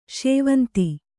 ♪ śevanti